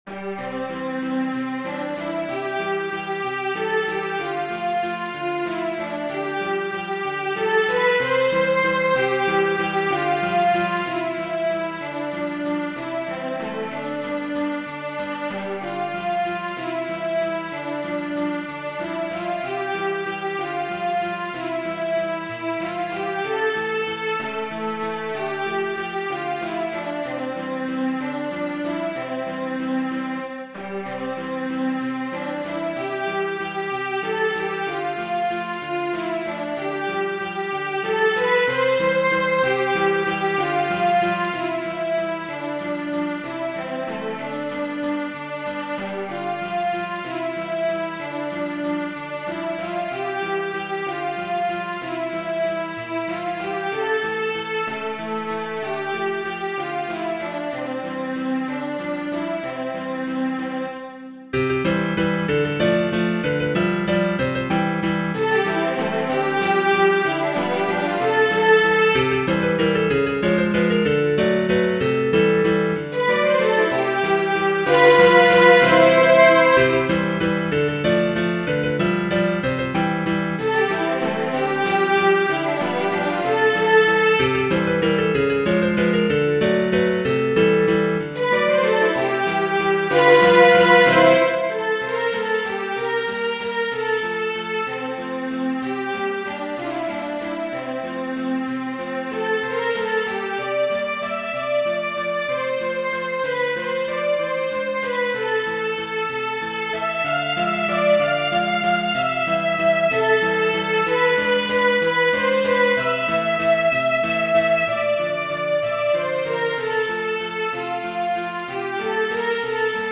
Lehar, F. Genere: Ballabili Questo valzer venne commissionato a Franz Lehàr dalla Principessa Pauline von Metternich per il Ballo di gala del 27 gennaio 1902, che si tenne nel Sophiensale di Berlino. Gold und Silber Waltz Gold und Silber Waltz letto 199 volte